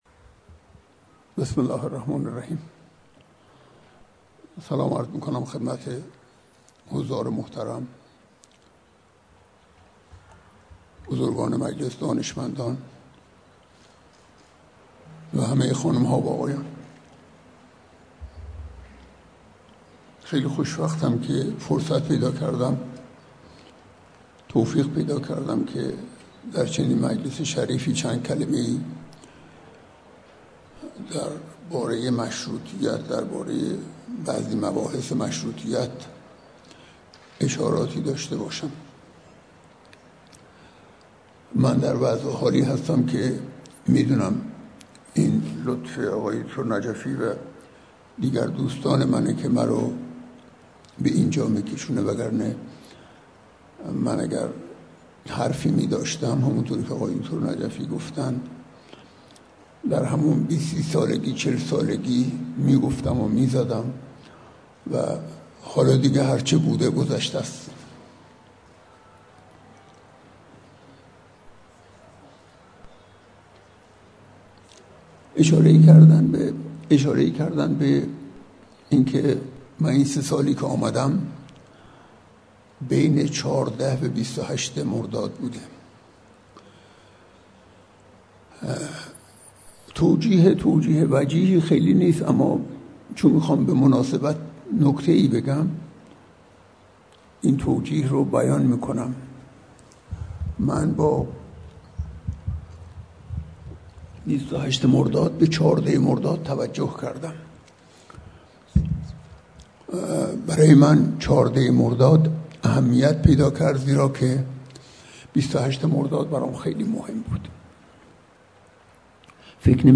سخنرانی دکتررضاداوری اردکانی رئیس فرهنگستان علوم جمهوری اسلامی ایران درهمایش تاملاتی پیرامون تحولات فکری درعصرمشروطه - خانه بیداری اسلامی